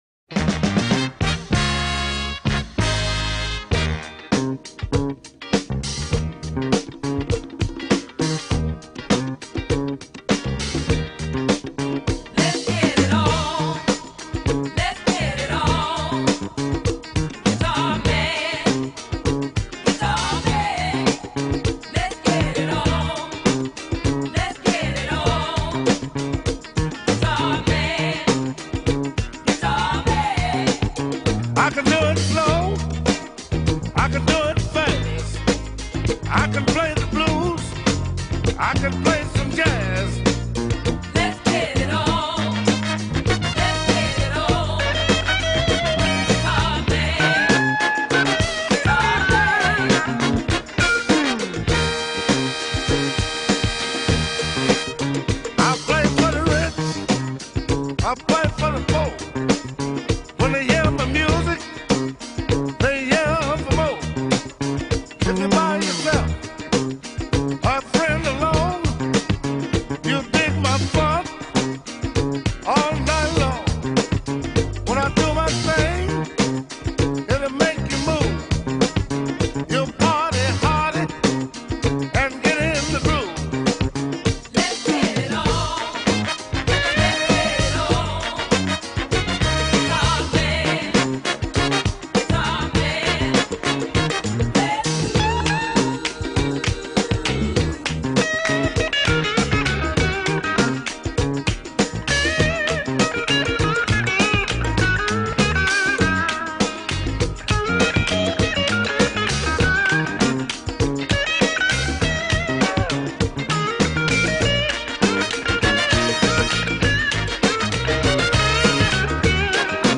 qualite de base